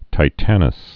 (tī-tănəs, -tānəs, tĭ-)